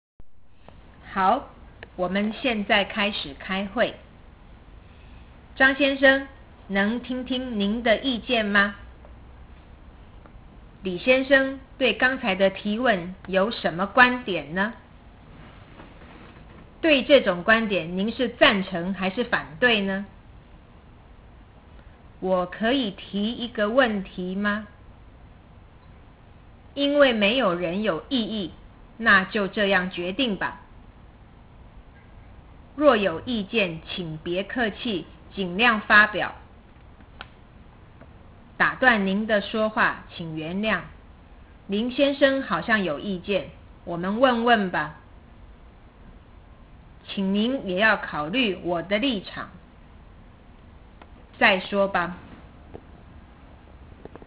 ( )内の数字は声調です。